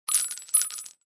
descargar sonido mp3 bala 3